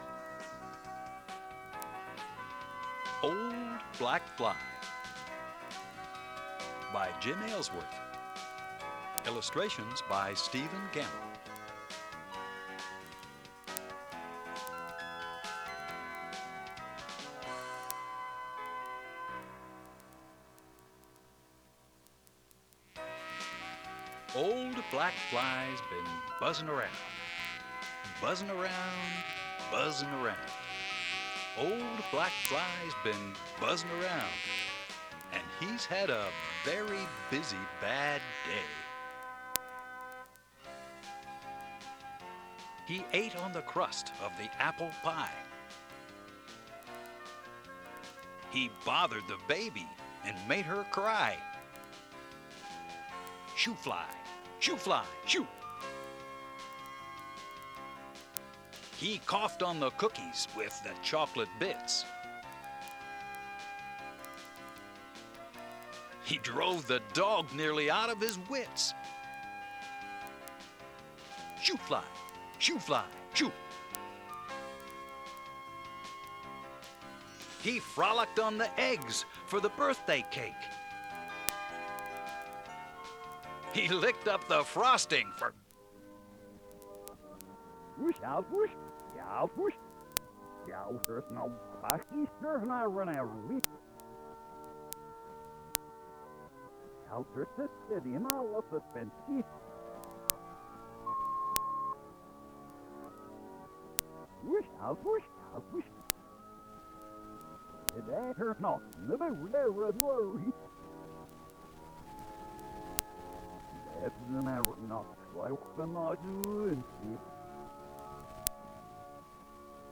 DOWNLOAD AUDIO NOVEL